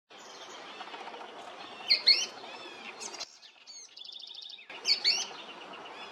Sooty-fronted Spinetail (Synallaxis frontalis)
Life Stage: Adult
Location or protected area: General Pico
Condition: Wild
Certainty: Recorded vocal
Pijui-Frente-gris-MP3.mp3